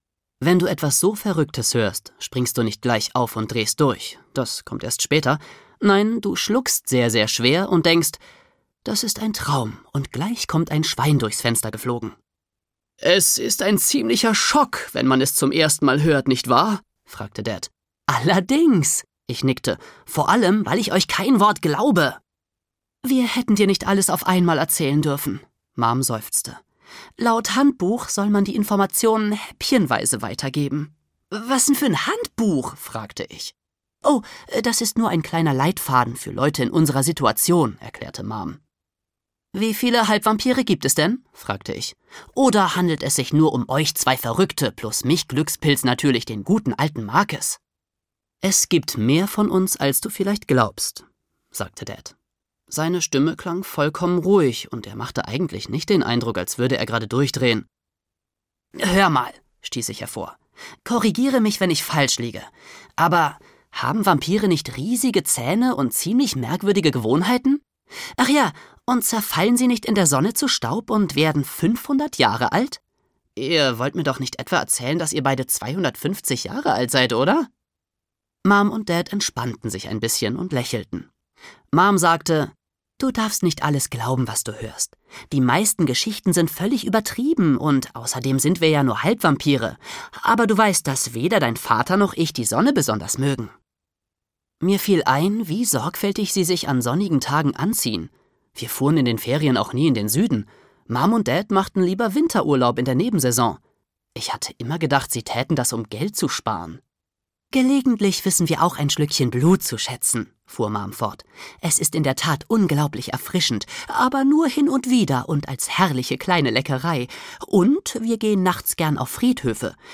Wie man 13 wird und überlebt (Wie man 13 wird 1) - Pete Johnson - Hörbuch